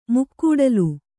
♪ mukkūḍalu